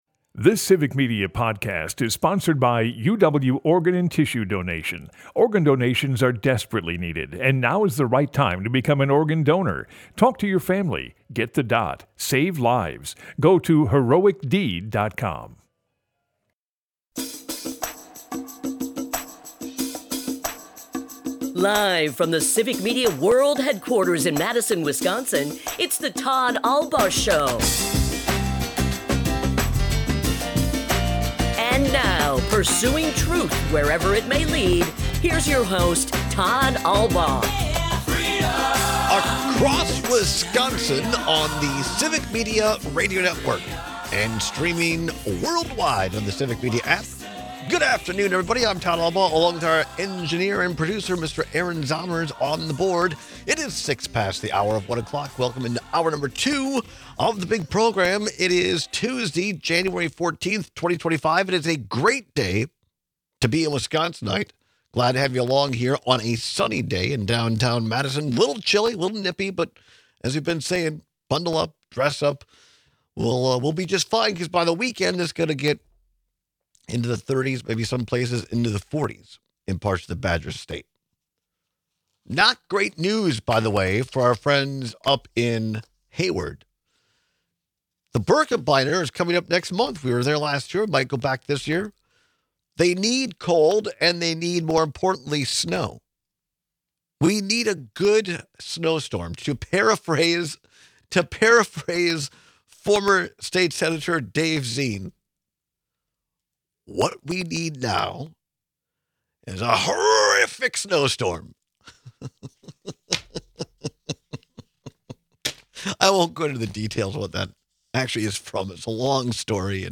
Broadcasts live 12 - 2p across Wisconsin.
We take a ton of calls and texts spanning music, politics and more.
To wrap up today’s show, we listen to some audio from today’s confirmation hearing for Defense Secretary nominee Pete Hegseth.